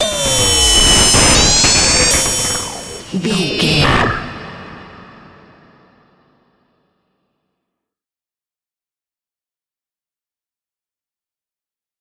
In-crescendo
Aeolian/Minor
scary
tension
ominous
dark
suspense
eerie
strings
synth
ambience
pads